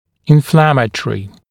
[ɪn’flæmət(ə)rɪ][ин’флэмэт(э)ри]воспалительный